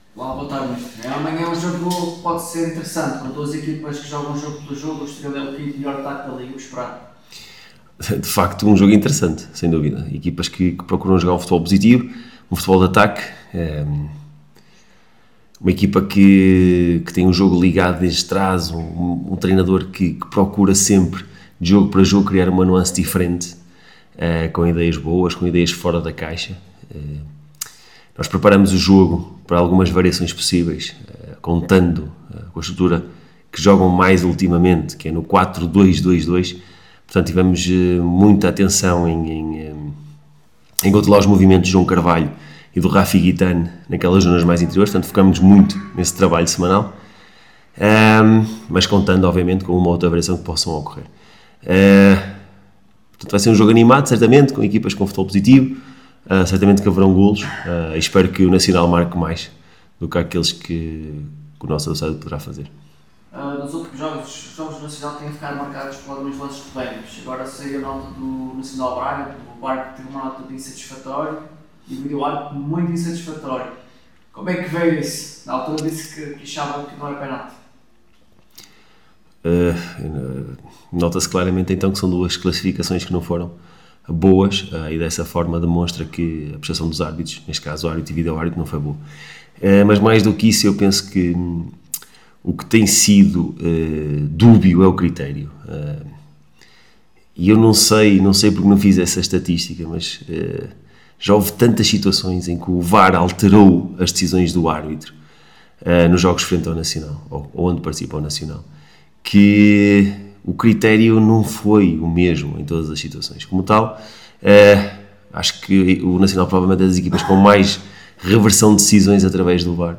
Na conferência de imprensa de antevisão ao jogo